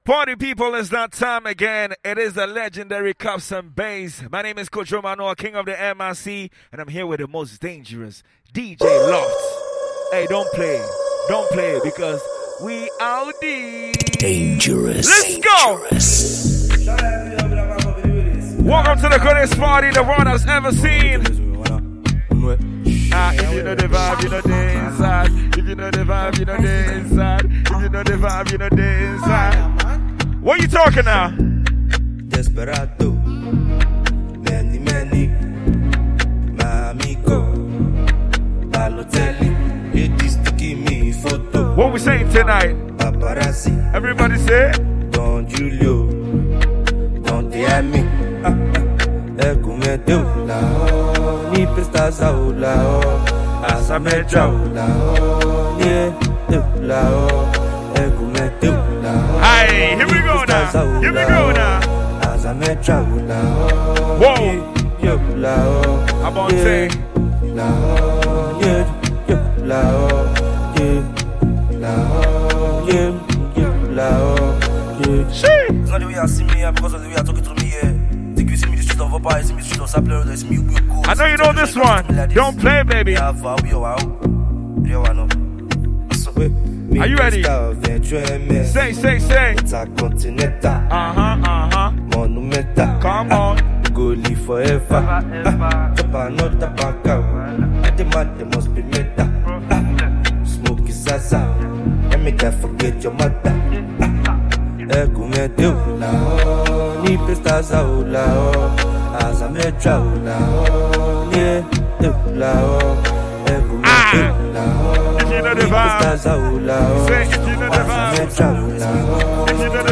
a banging vibe and a free MP3 download
DJ Mixtape
Ghana Afrobeat